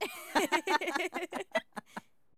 Hahahahaha